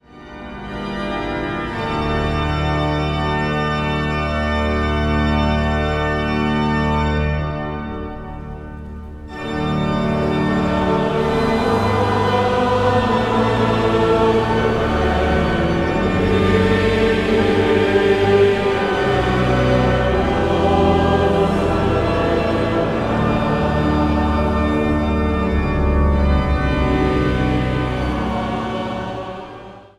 Niet ritmische samenzang vanuit de Bovenkerk te Kampen
Zang | Samenzang